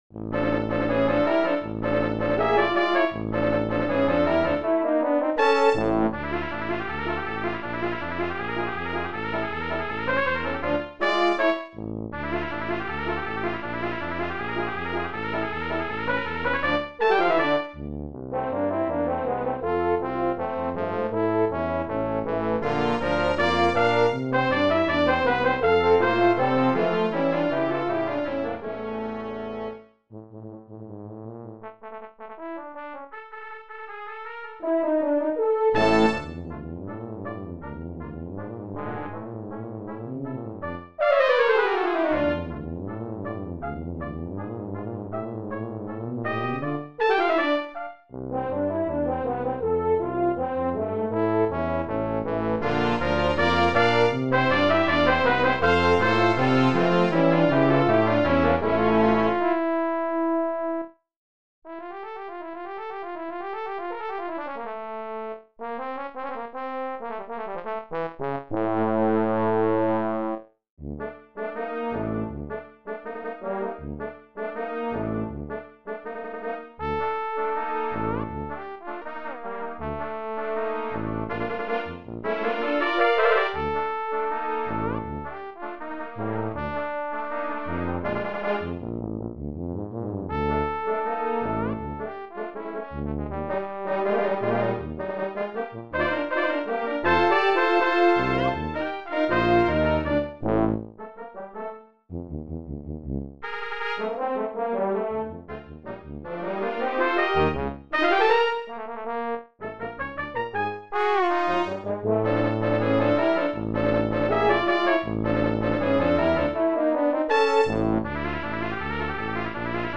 Brass Quintet
Listen to synthesized versions.